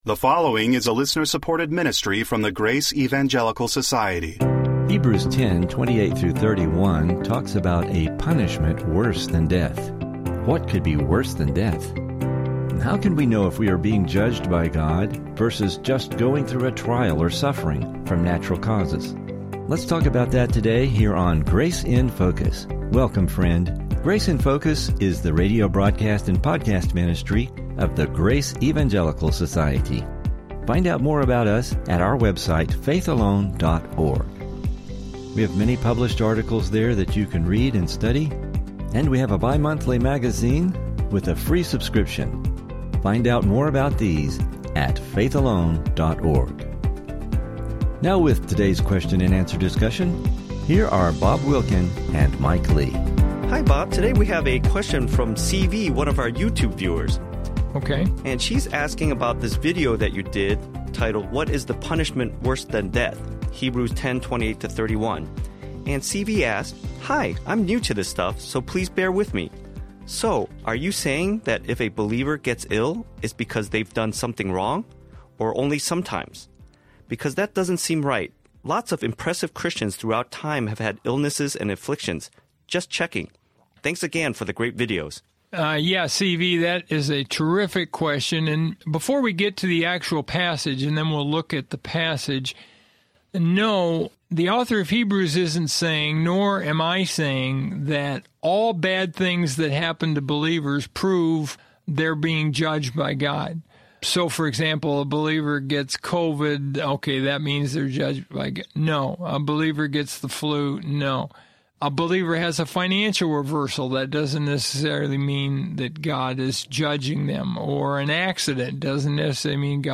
How can we know if we’re being judged by God vs. just going through a trial or suffering from natural causes? How can believers be punished worse than death? This will be an interesting discussion.